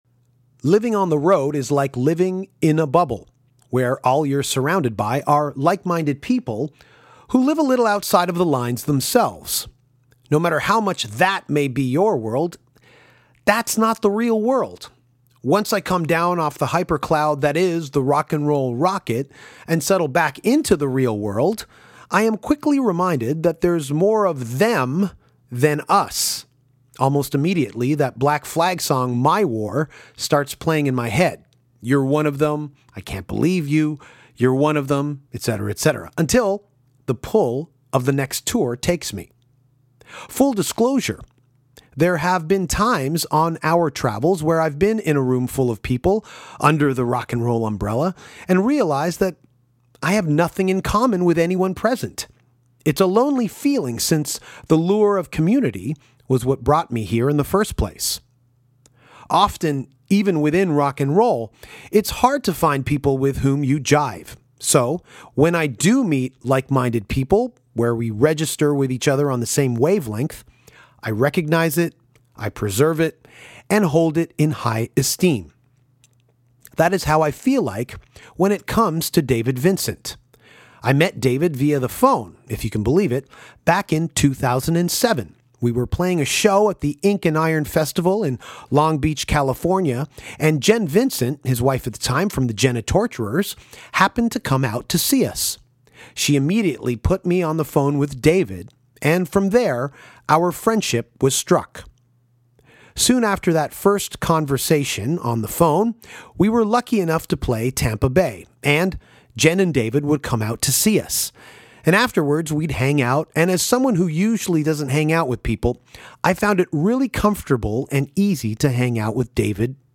The one and only David Vincent caught up with Danko to talk about all his new musical projects.